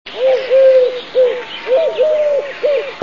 Sierpówka - Streptopelia decaocto